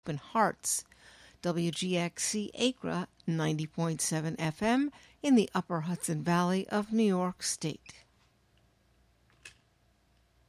Broadcasting live from Catskill, NY.